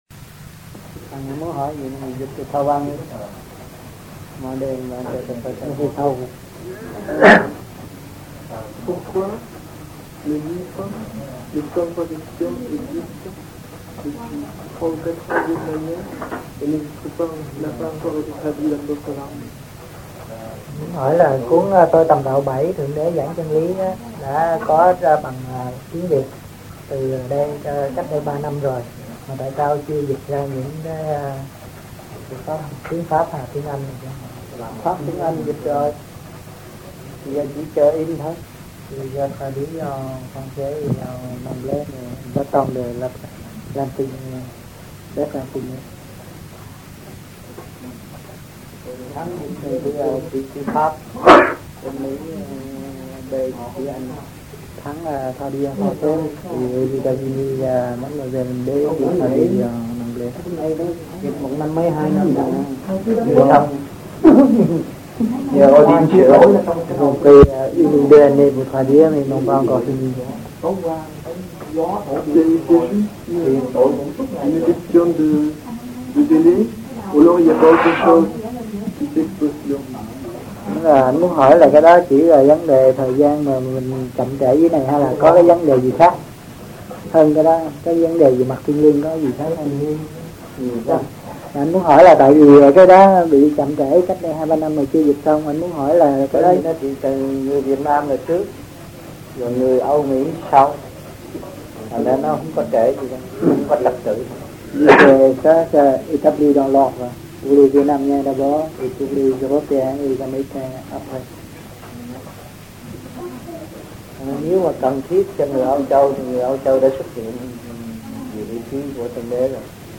Paris, France Trong dịp : Sinh hoạt thiền đường >> wide display >> Downloads